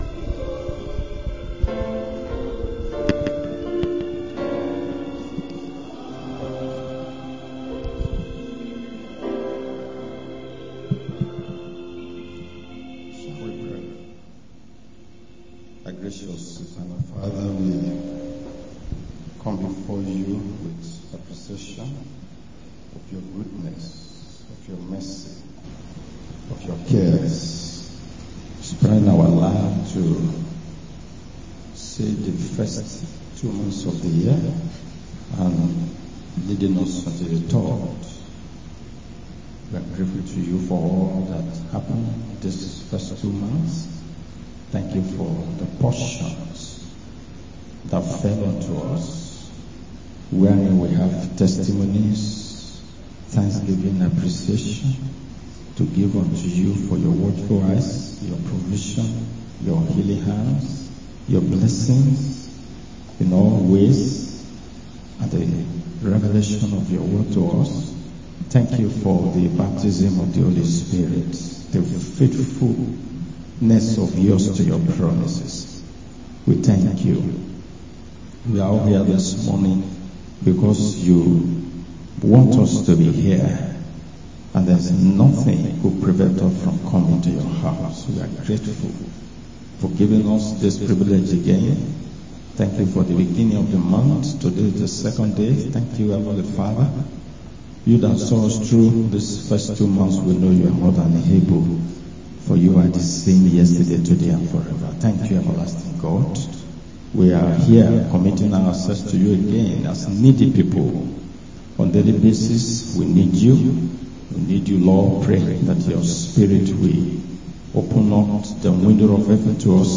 Christ's Bride Tabernacle